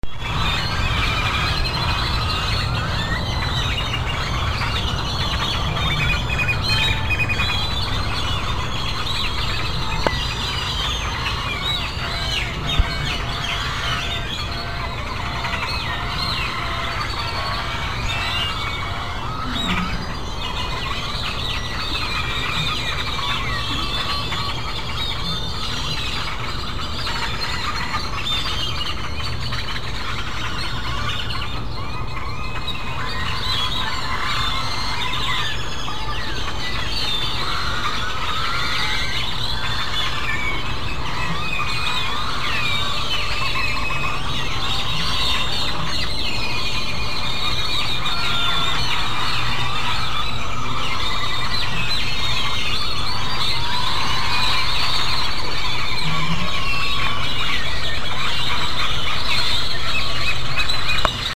De clarinero is een veelzijdige fluiter.
Dit is een boom in Gogorrón (18 juni 2003) met een stuk of honderd clarinero's.
Clarineros (MP3 sound, 960 KB)